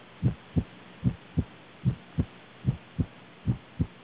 Functional/Innocent Murmors (.au, 32KB